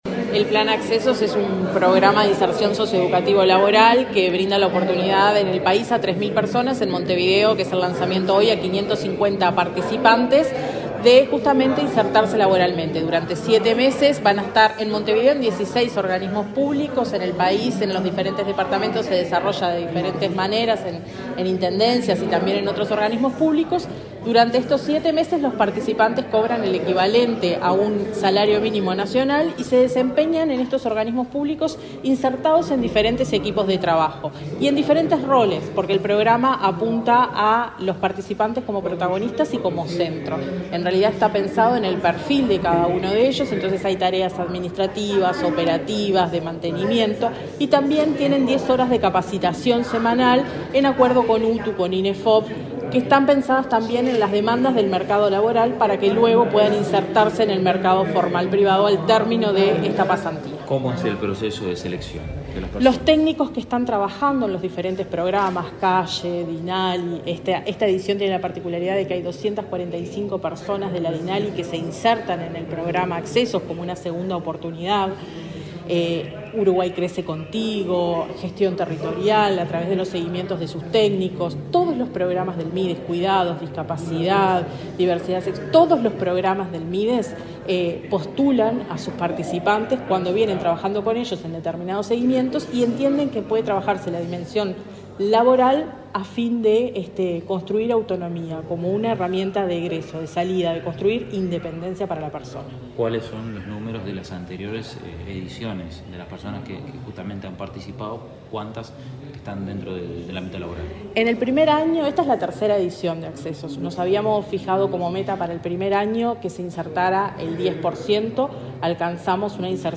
Declaraciones de la directora nacional de Gestión Territorial del Mides, Carolina Murphy